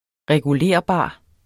Udtale [ ʁεguˈleɐ̯ˀˌbɑˀ ]